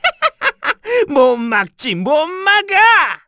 Bernheim-Vox_Skill4_kr_z.wav